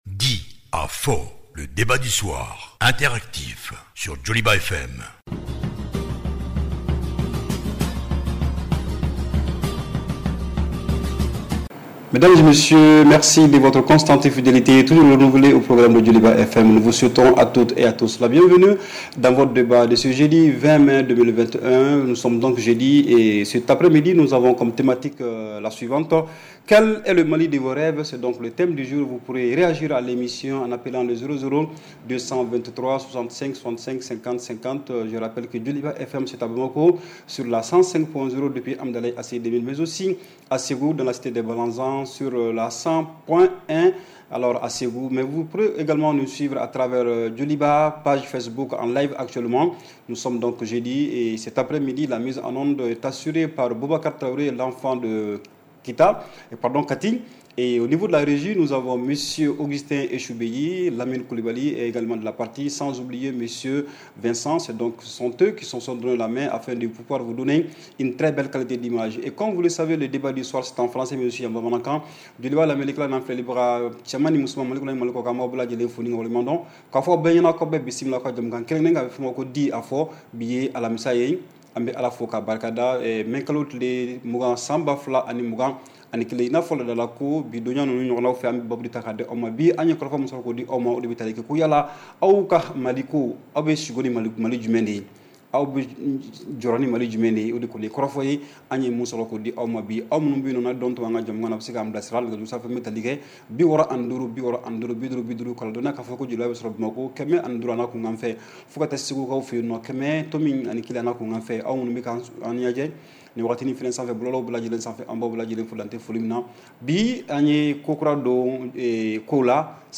REPLAY 20/05 – « DIS ! » Le Débat Interactif du Soir